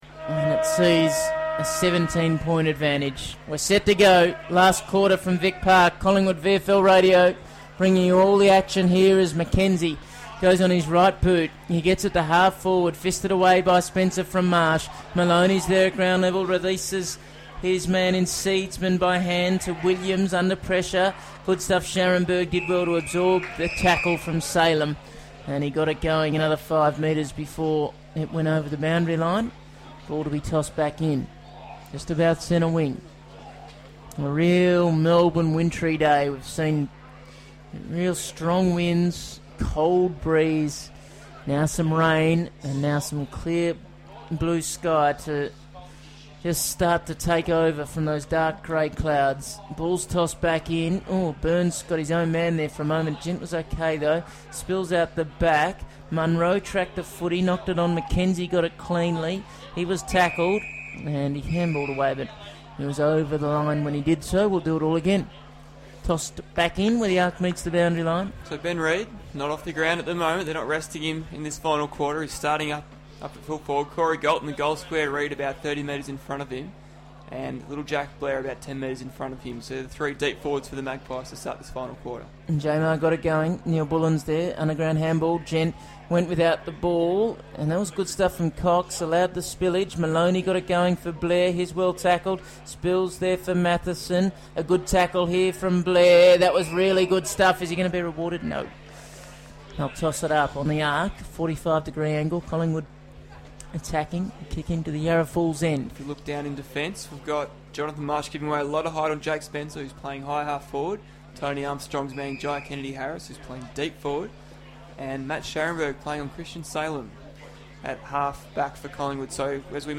Listen to Collingwood Radio's commentary of the final quarter of Collingwood's clash with the Casey Scorpions at Victoria Park in round 15, 2015.